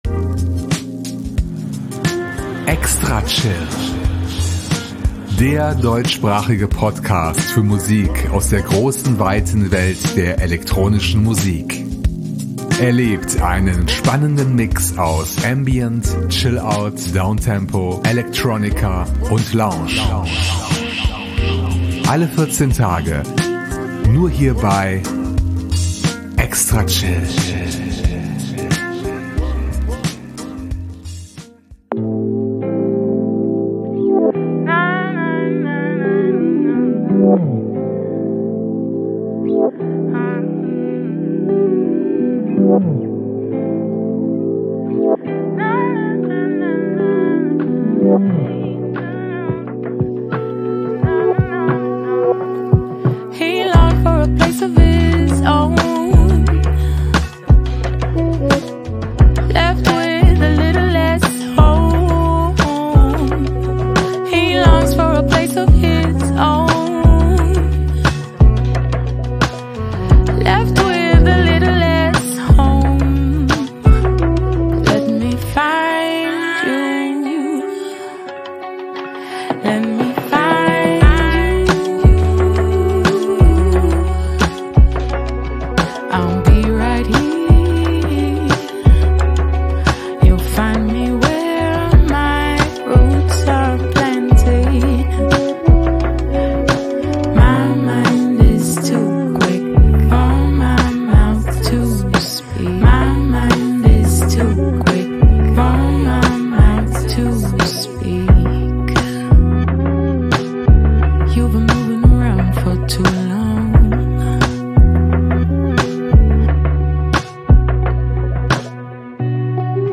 Viele spannenden Electronica-Tracks erwarten Euch